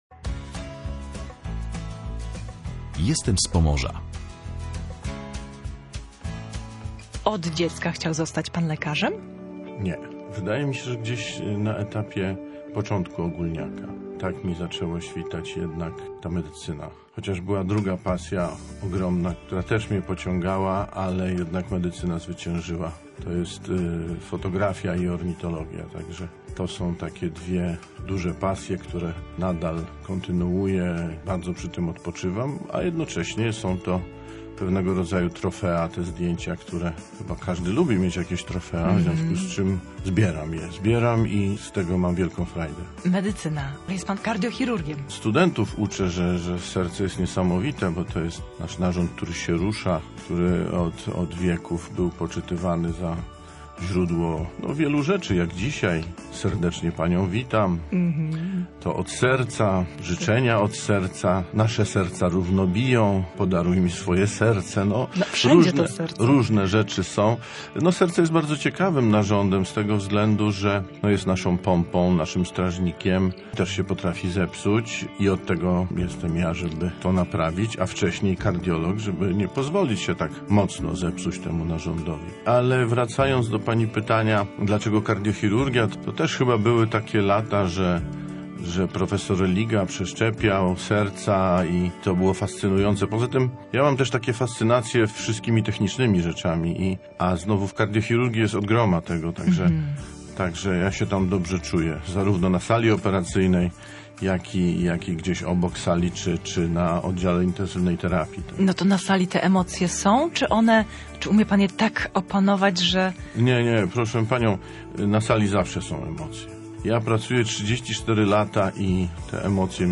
Mówi o niej z czułością i spokojem, jak o czymś bardzo osobistym.